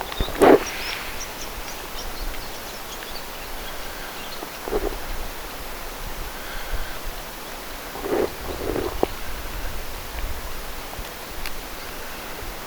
nuori haarapääskynen lentää ylitse
lauluharjoitellen
kuuluu, niin olisi saanut tästä paljon paremman äänityksen.
yli_lentaa_nuori_haarapaaskynen_lauluharjoitellen.mp3